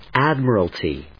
音節ád・mi・ral・ty 発音記号・読み方
/ˈædm(ə)rəlti(米国英語), ˈædmɜ:ʌlti:(英国英語)/
フリガナアドマラルティー